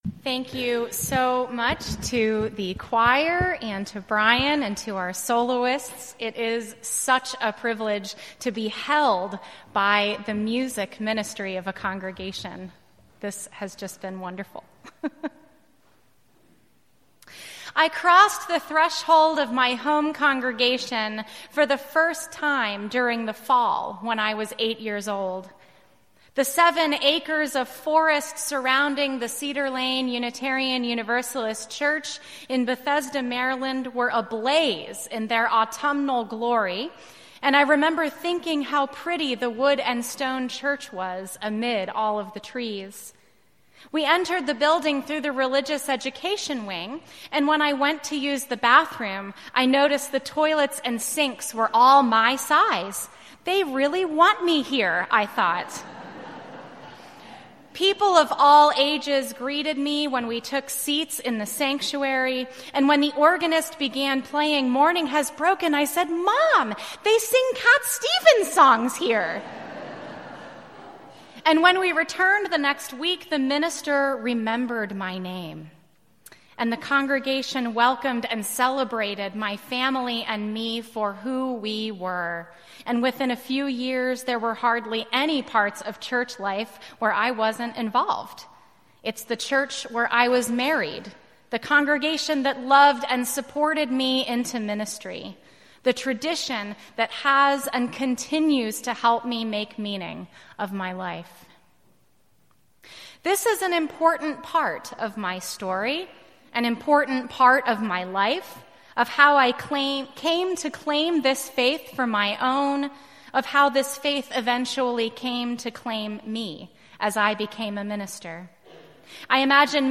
Candidate Week: Ministerial Candidates' 1st Sermon - Unitarian Universalist Church of Berkeley